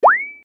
на смс
короткий футуристичный звучок